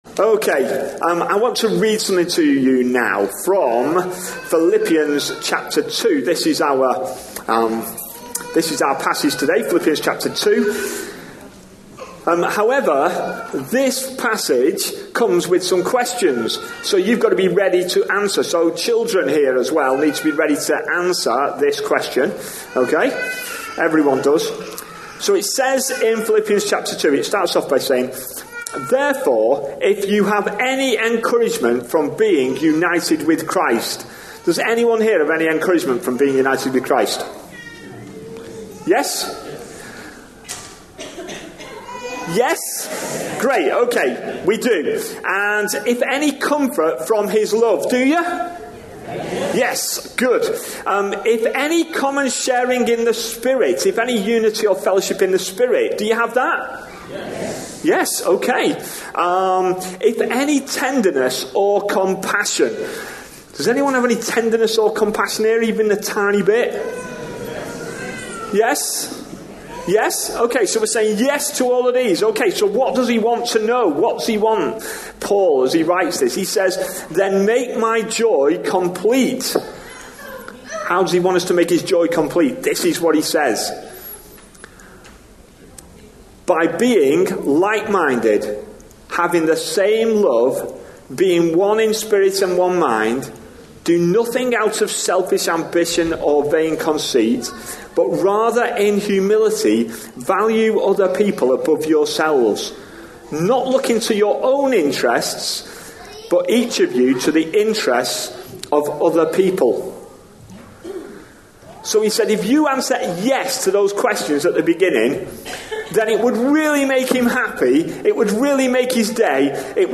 A sermon preached on 12th August, 2018, as part of our Summer 2018. series.